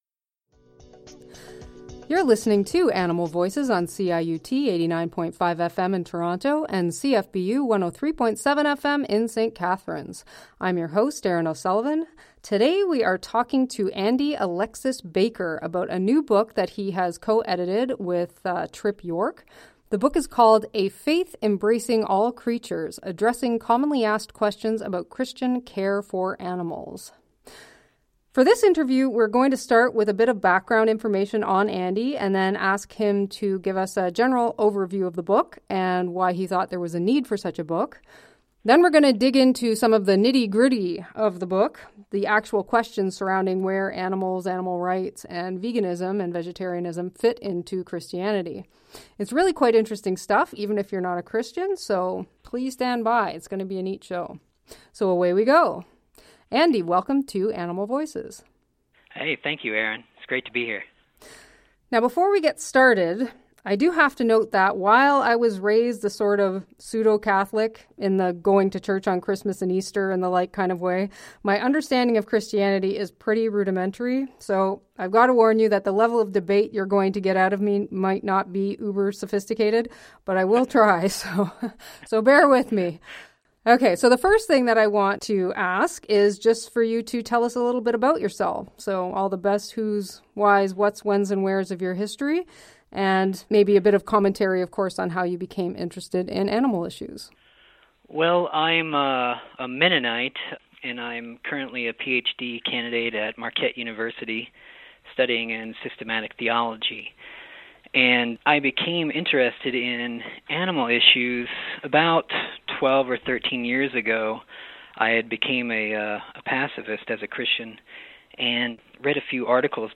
Whether you’re a Christian trying to reconcile your compassion for animals with your faith, an animal activist trying to engage with Christians, or just fascinated by religious history and the nuances of Christian scripture, this interview is sure to captivate!